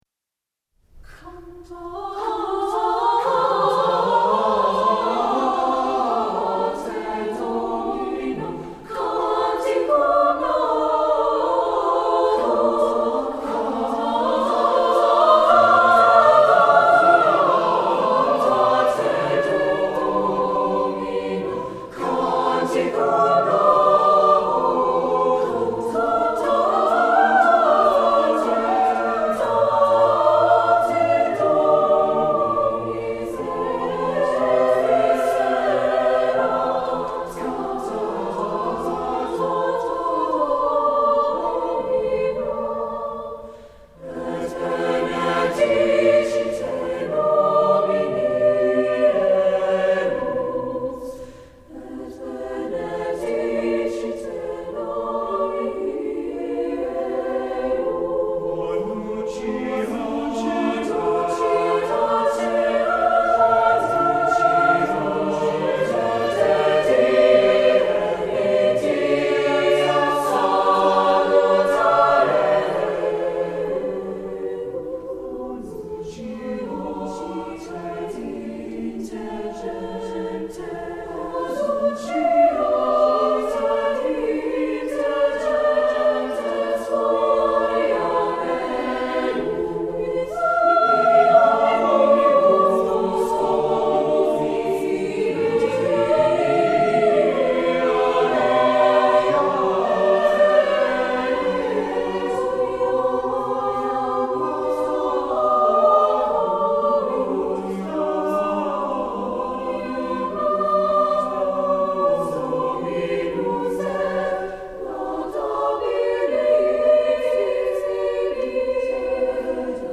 Voicing: SSATB